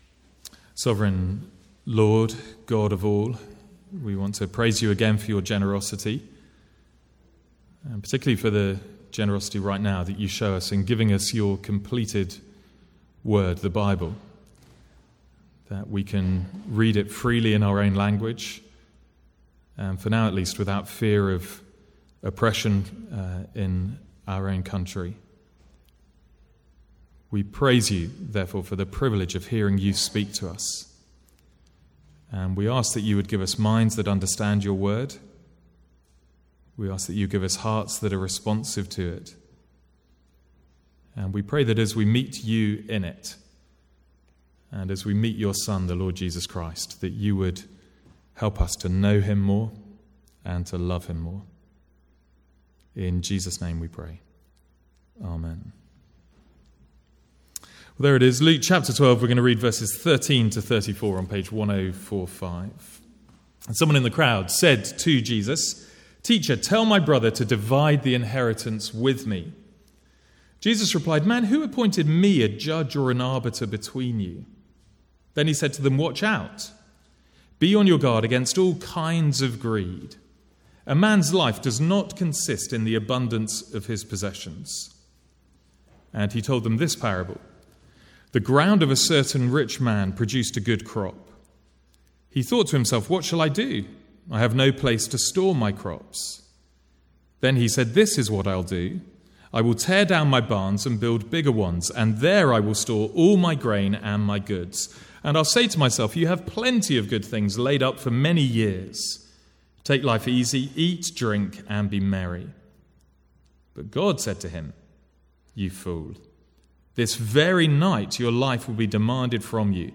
Sermon from Giving Sunday.